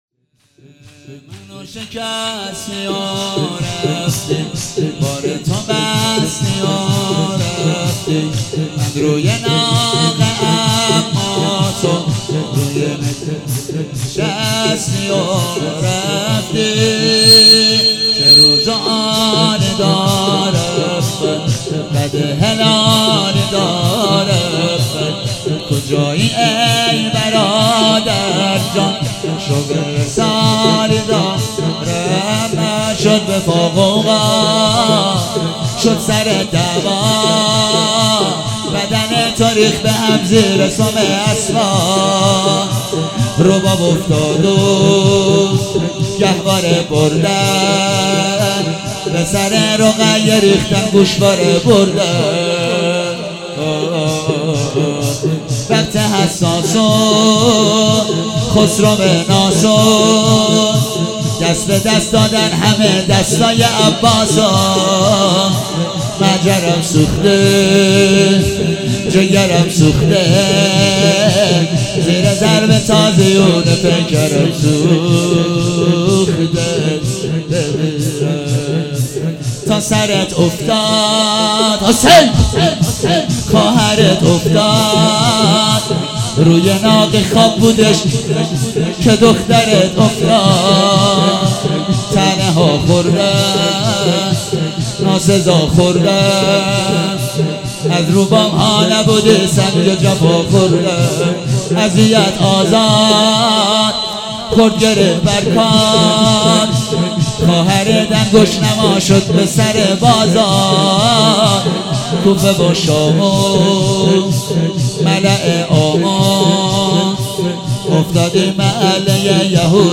صفر المظفر
شور